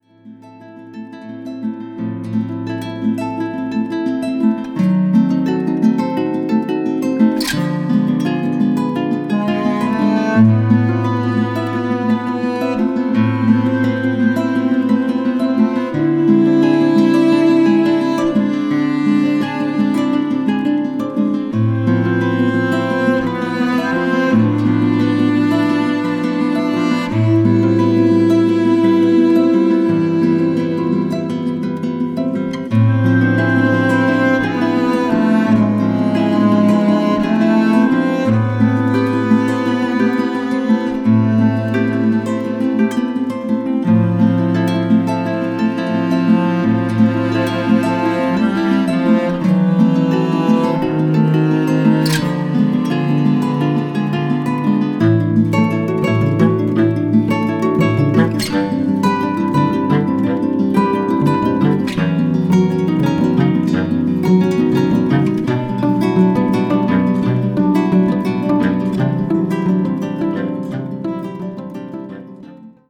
gitarre, bass, percussion
flöte
klarinette
cello